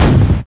06.2 KICK.wav